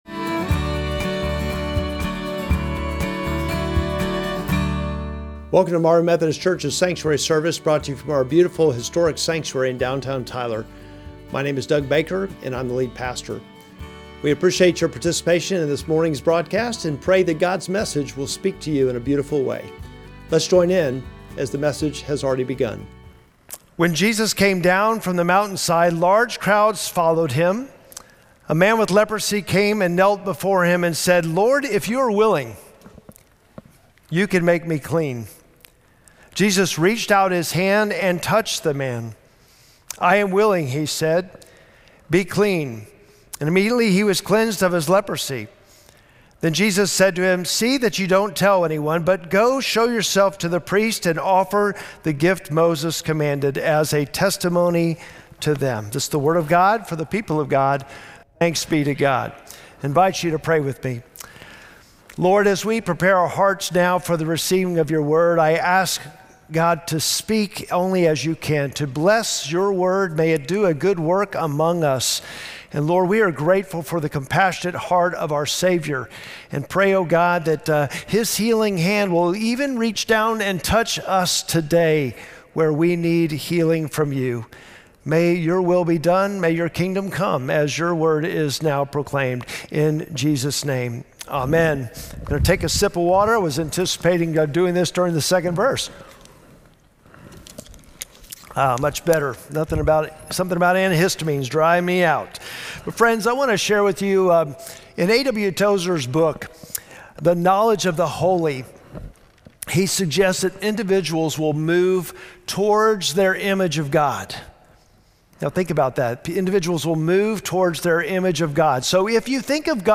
Sermon text: Matthew 8:1-4